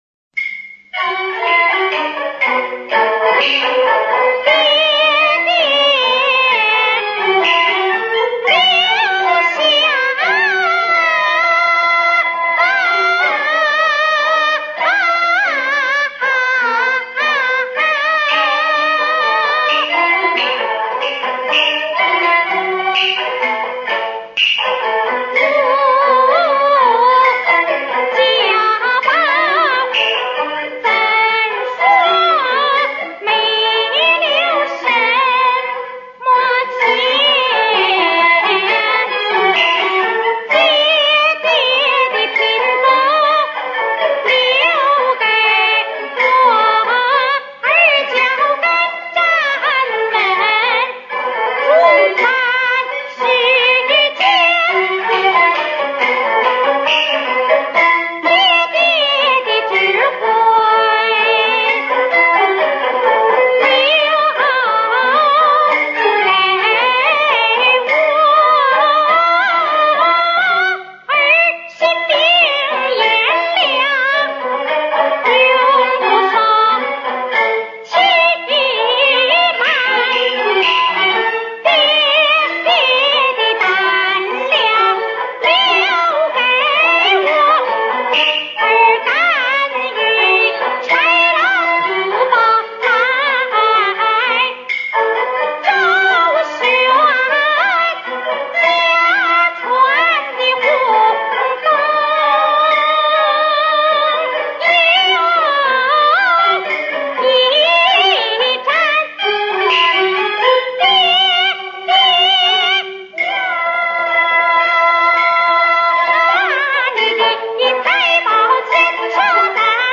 本辑为其中的旦角篇，所有唱腔均为历史录音。